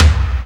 prcTTE44006tom.wav